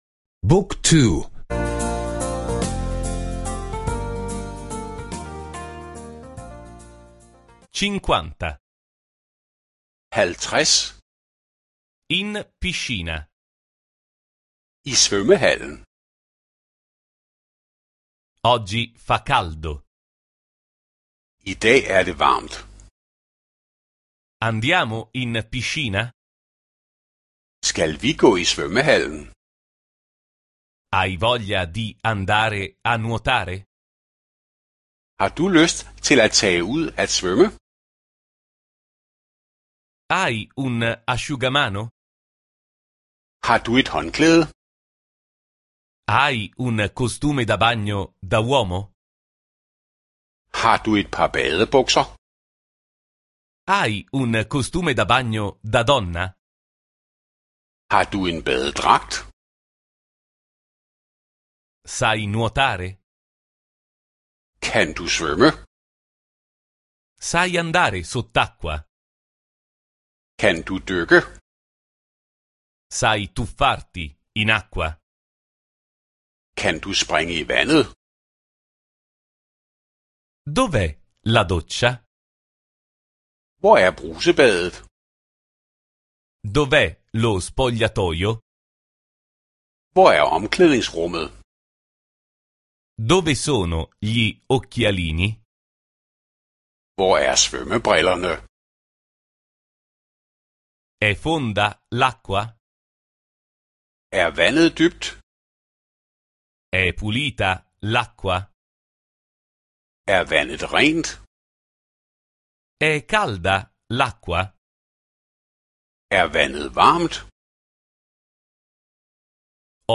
Audio corso danese — ascolta online